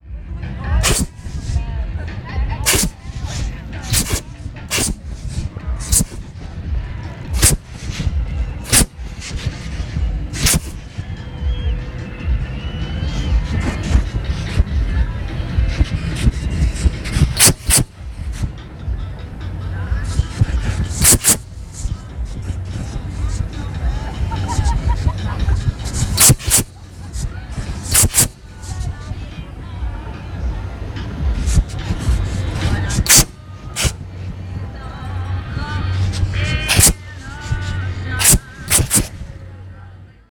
bm_fire_thrower.L.wav